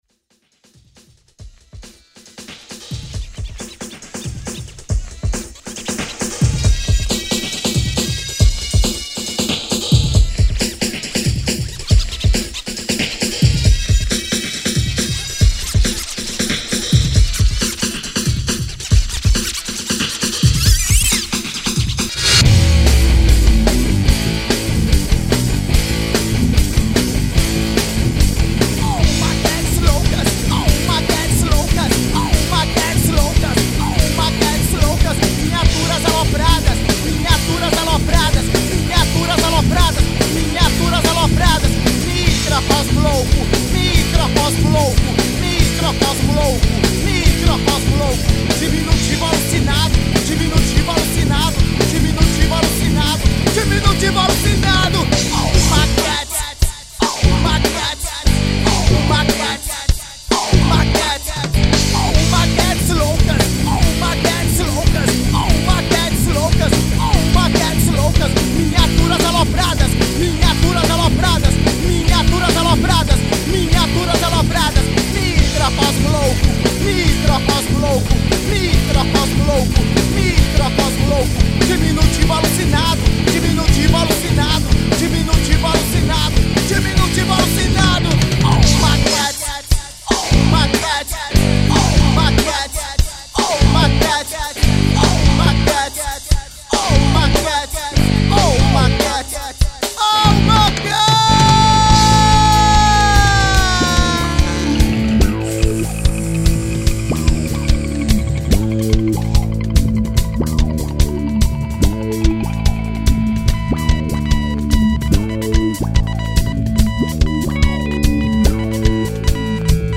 1943   03:55:00   Faixa: 1    Rock Nacional